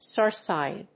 音節stár sìgn